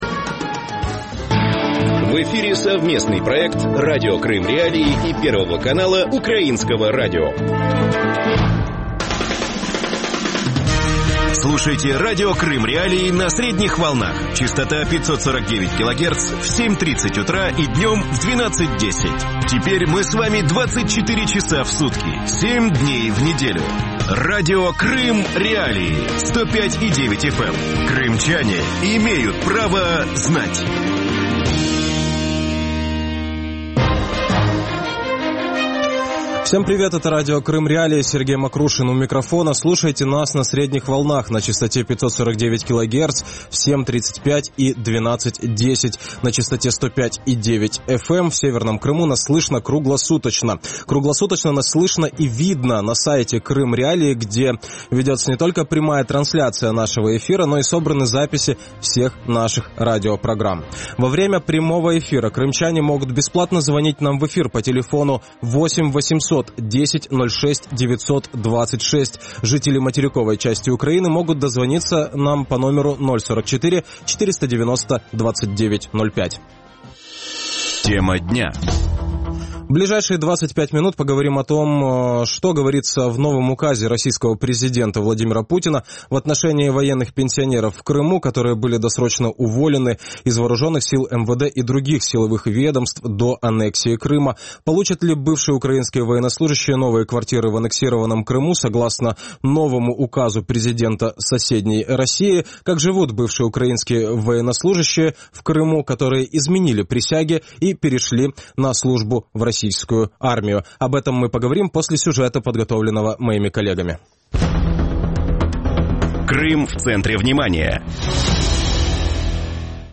Как живут бывшие украинские военнослужащие в Крыму, которые изменили присяге и перешли на службу в российскую армию? Гости эфира